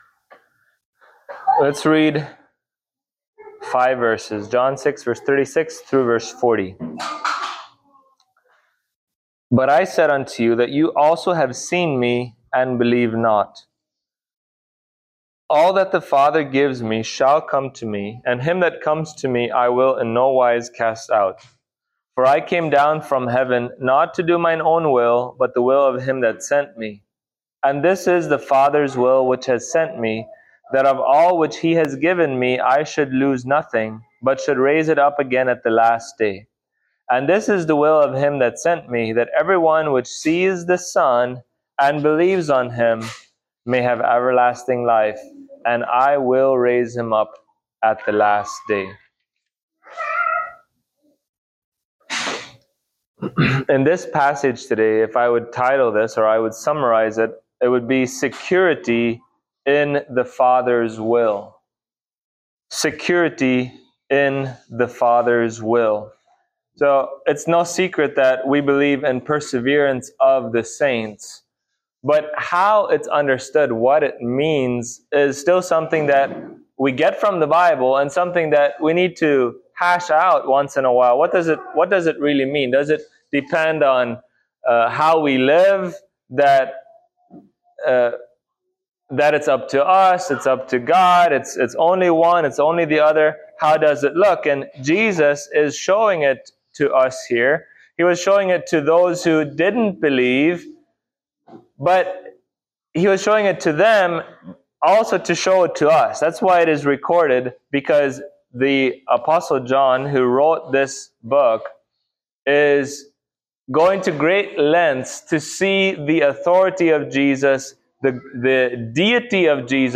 Passage: John 6:36-40 Service Type: Sunday Morning